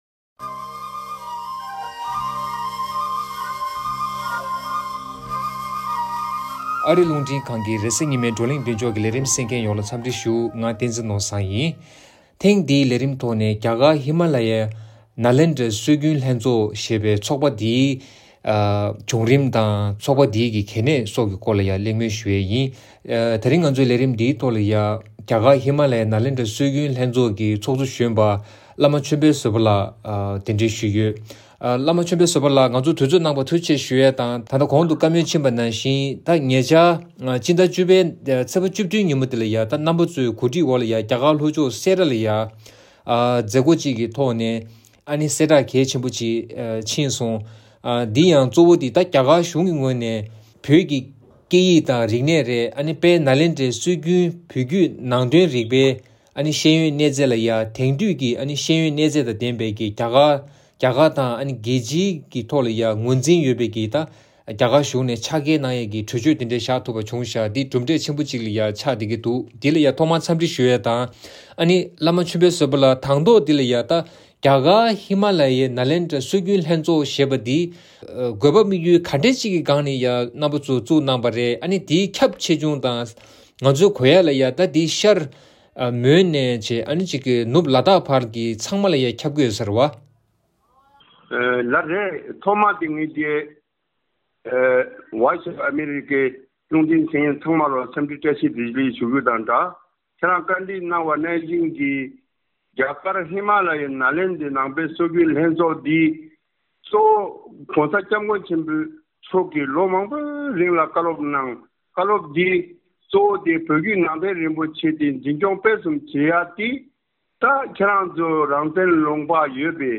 གླེང་མོལ་ཞུས་ཡོད། ཚོགས་པ་དེ་ནི་ཤར་མོན་ནས་ནུབ་ལ་དྭགས་བར་ཁྱབ་པའི་སྒྲིག་འཛུགས་གལ་ཆེན་ཞིག་ཡིན་པ་རེད།